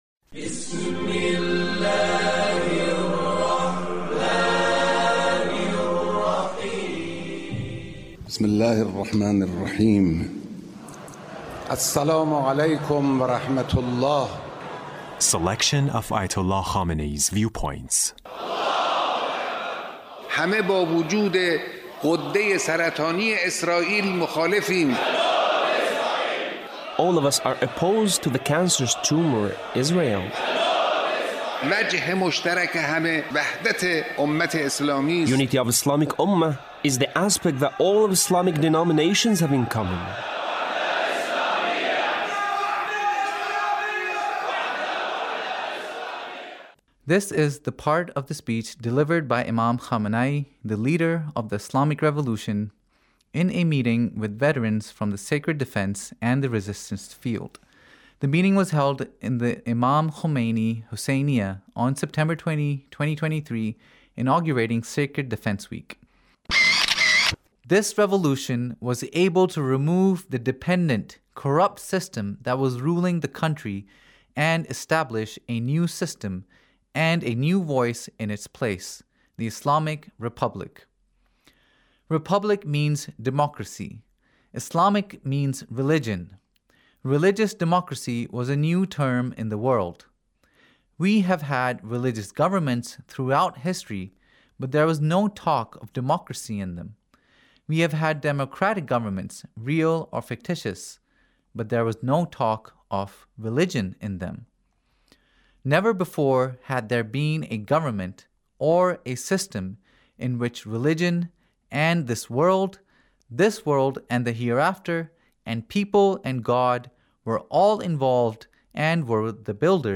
Leader's Speech (1872)
Leader's Speech about Sacred defense